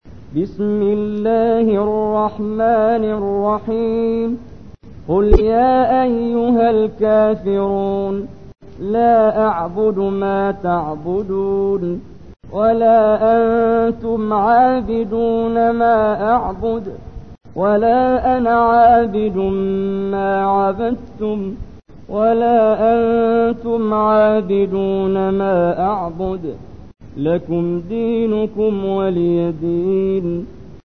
تحميل : 109. سورة الكافرون / القارئ محمد جبريل / القرآن الكريم / موقع يا حسين